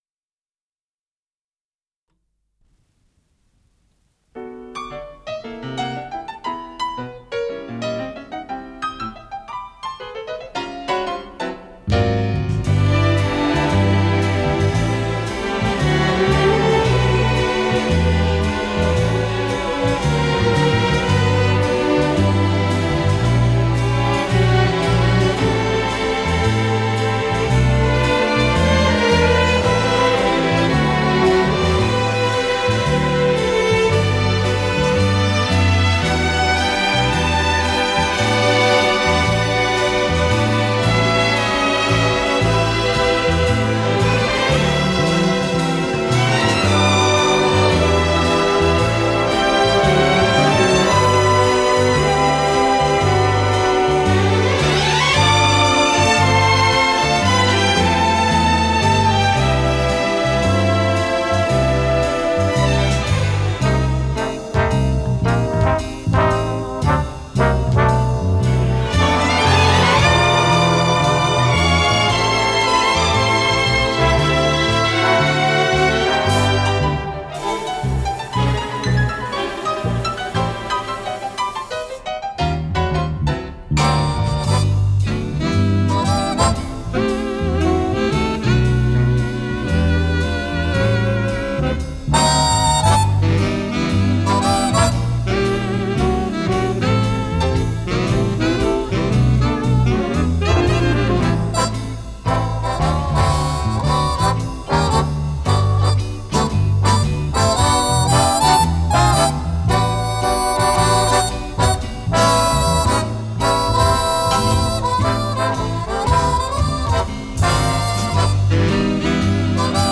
Скорость ленты38 см/с
ВариантДубль моно